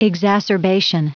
Prononciation du mot exacerbation en anglais (fichier audio)
Prononciation du mot : exacerbation